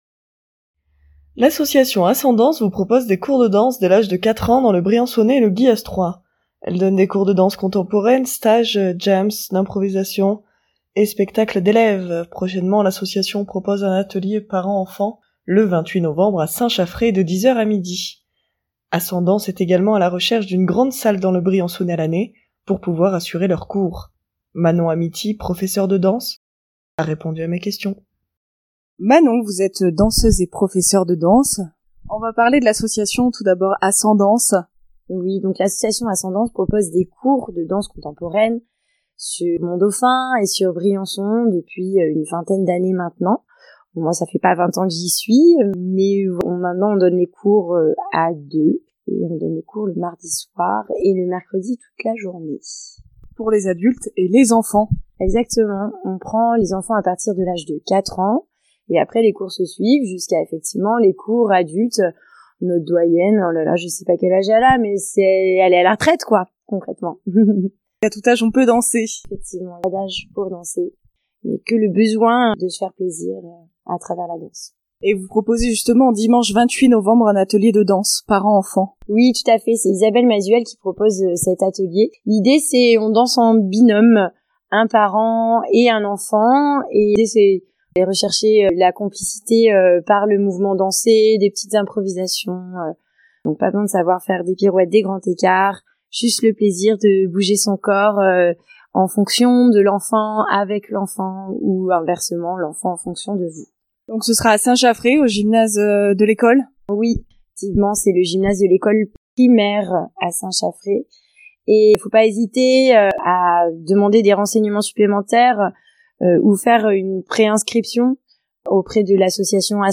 nous en dit plus au micro